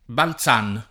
balZ#n], Balzani, Balzano